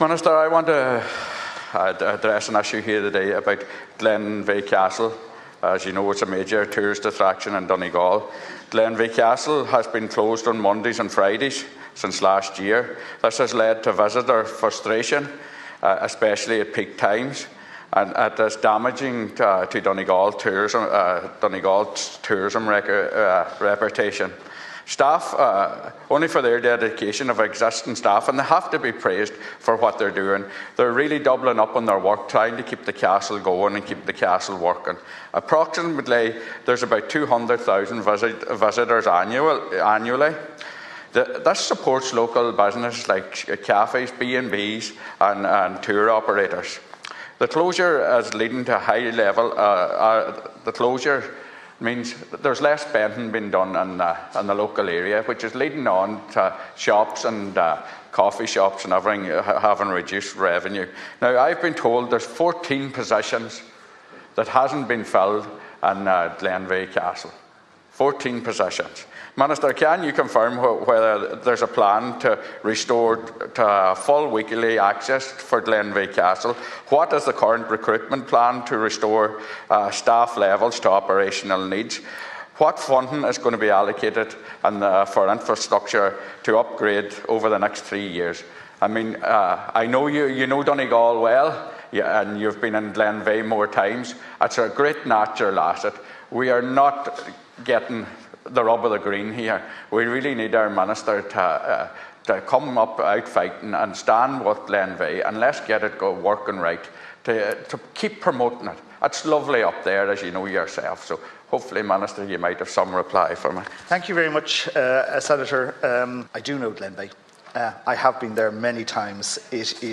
Senator Manus Boyle raised the issue in the chamber this morning, and urged Minister Colm Brophy to lobby to see what can be done to secure the 14 staff required to open the facility on a seven day a week basis.